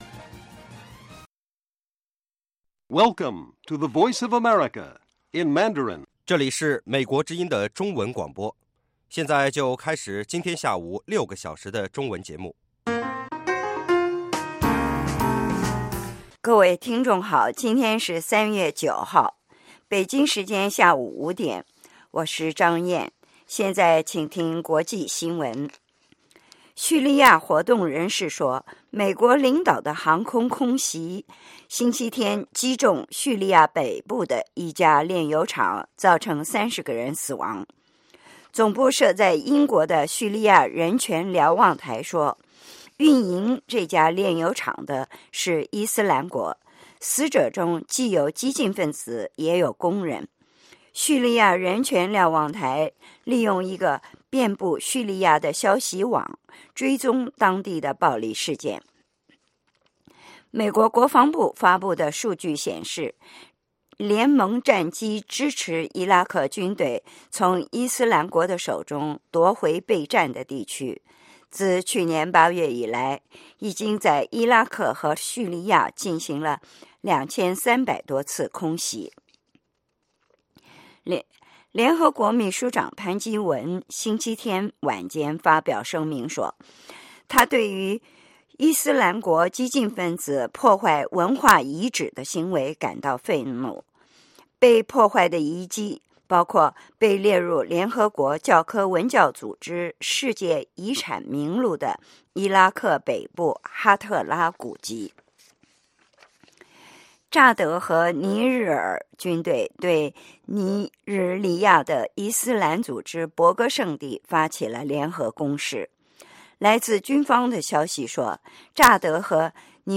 北京时间下午5-6点广播节目。 内容包括国际新闻和美语训练班（学个词， 美国习惯用语，美语怎么说，英语三级跳， 礼节美语以及体育美语）